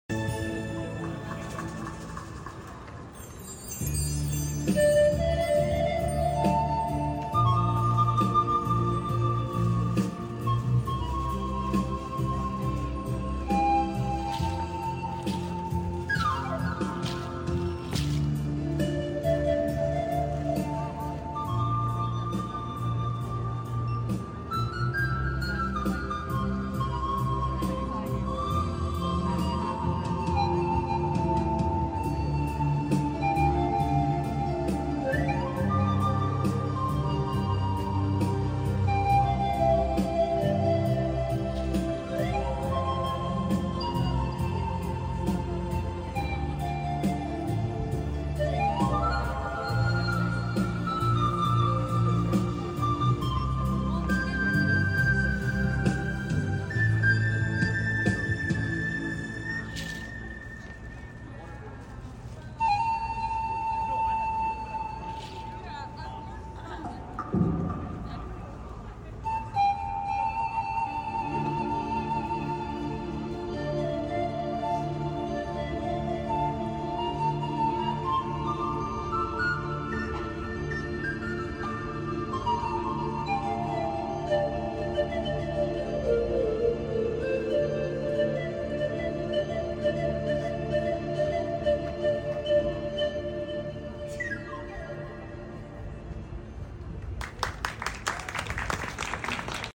Street performance , Granville island